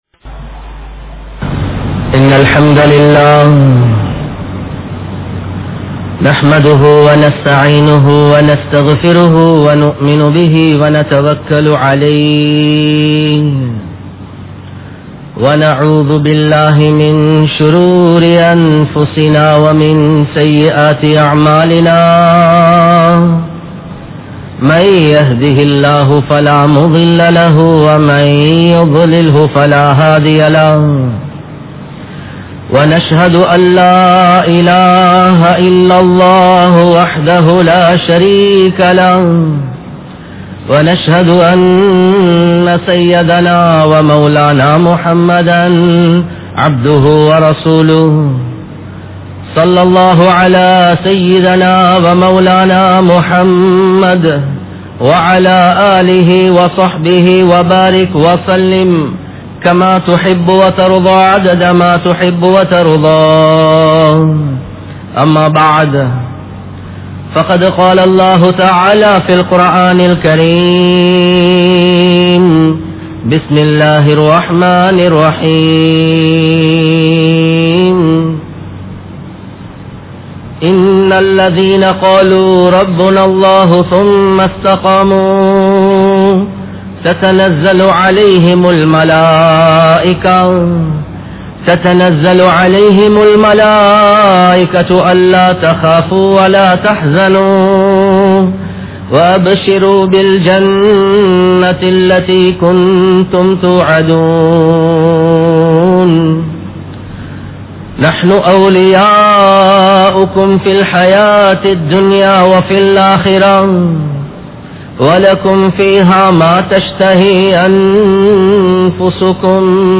Ulamaakkalin Ganniyam (உலமாக்களின் கண்ணியம்) | Audio Bayans | All Ceylon Muslim Youth Community | Addalaichenai
Kollupitty Jumua Masjith